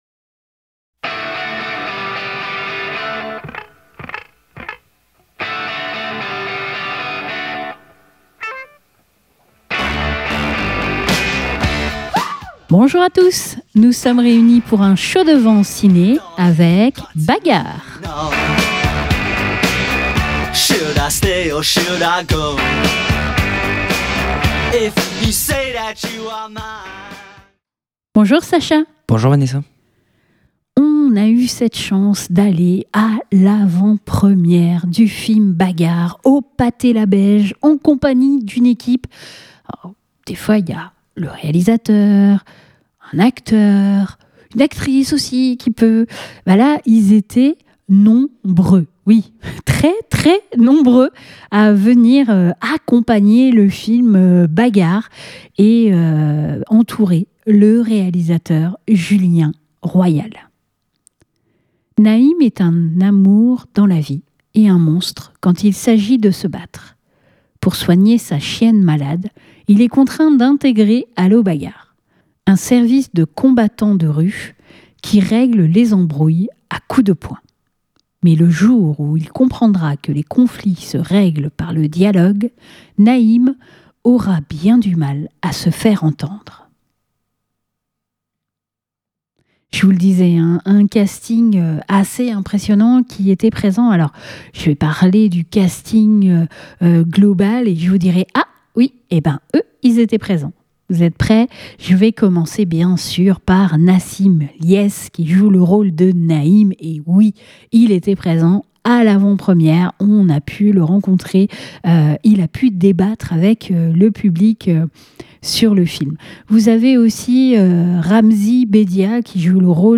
Et bien entendu que serait notre retour sans les avis du public.